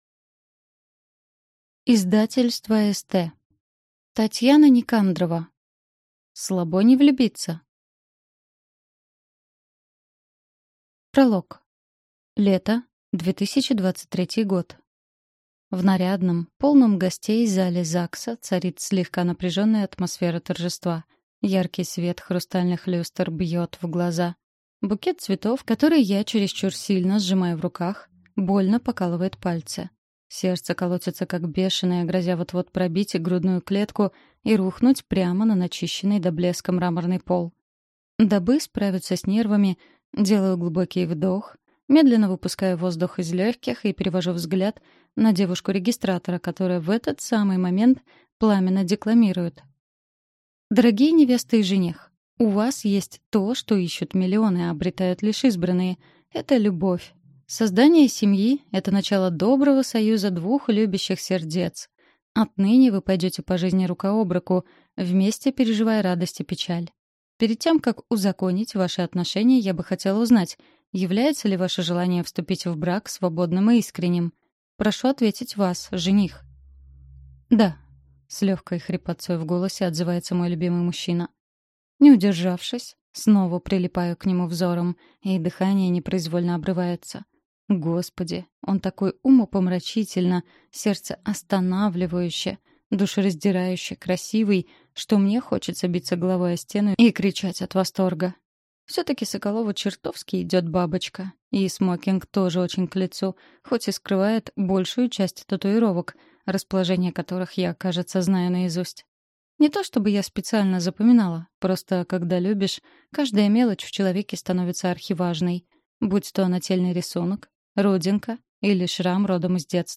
Аудиокнига Слабо не влюбиться?